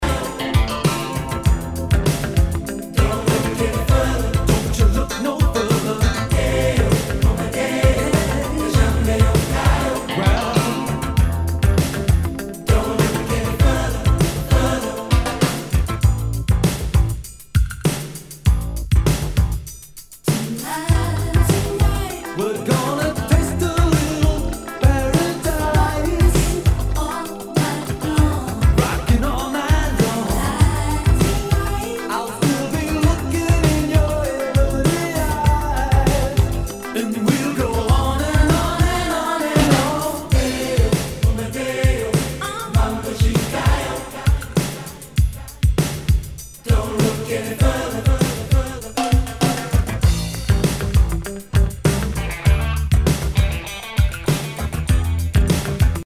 Nu- Jazz/BREAK BEATS
ナイス！ダウンテンポ / ポップ・ロック！